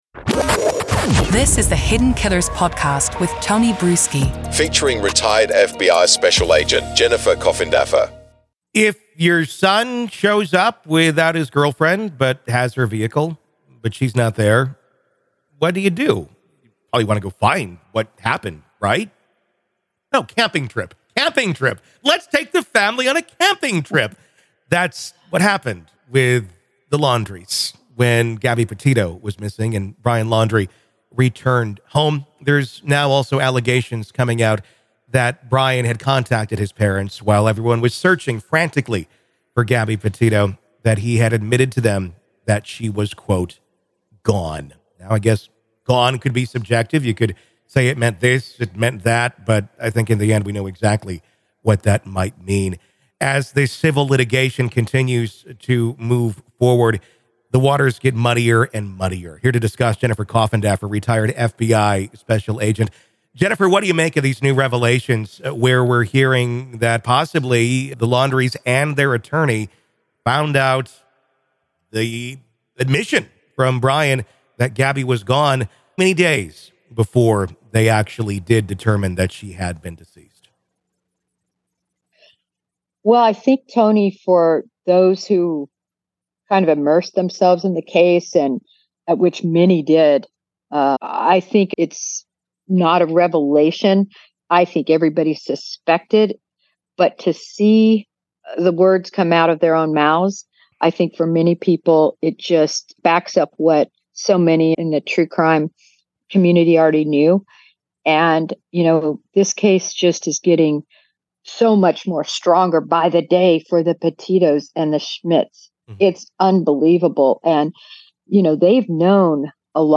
The conversation delves into the actions of Brian Laundrie's family following Petito's disappearance and Laundrie's return home without her.